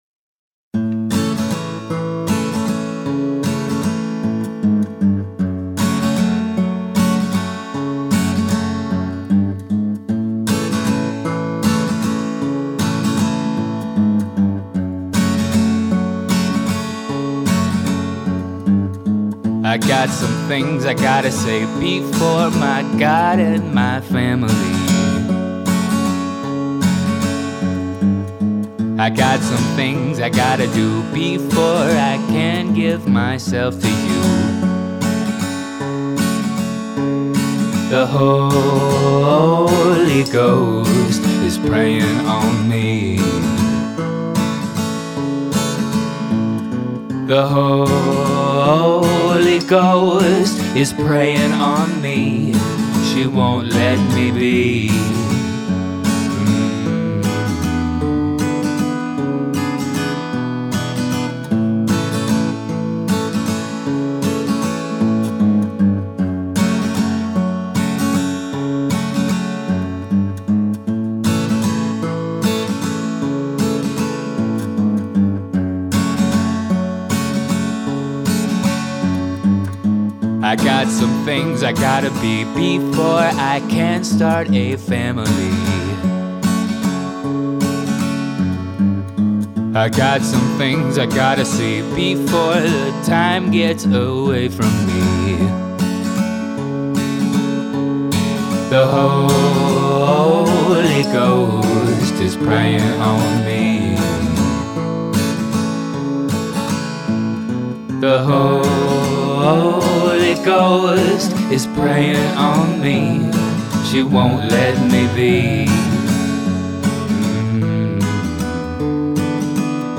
I have taken the rest of the feedback into consideration thinned out the background vocals, took the panning from 100% down to 25% (except for the bridge where I wanted it to jump out), and turned them down a bit.
This seems more balanced.
I felt the guitar in the remix sounded a bit mid heavy and preferred the original thinner version. I dont mind the vox way upfront as you have a good and interesting voice.